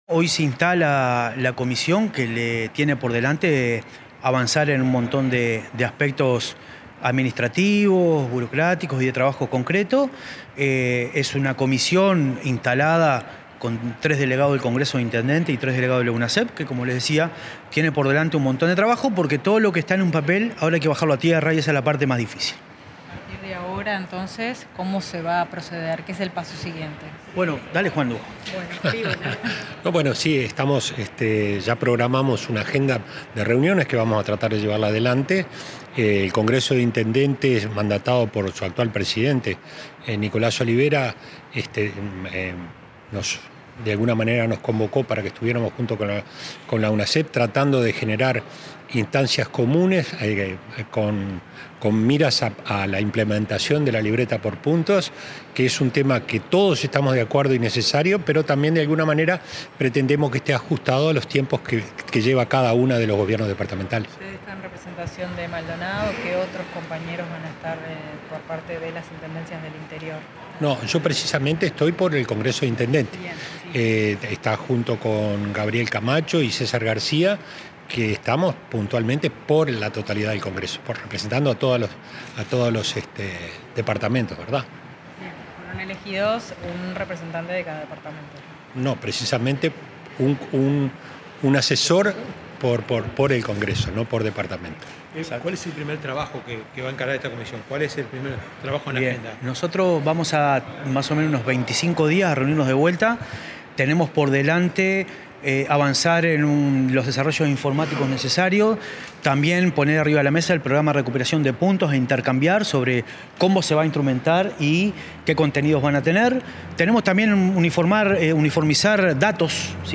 Declaraciones del presidente de Unasev